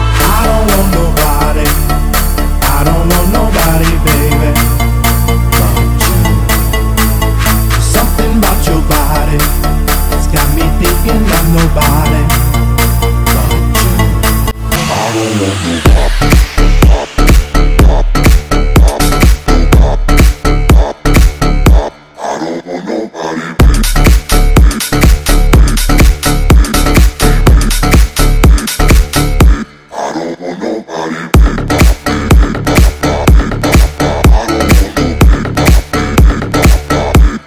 • Качество: 320, Stereo
мужской вокал
громкие
Electronic
чувственные
Bass House
electro house
электронный голос